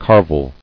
[car·vel]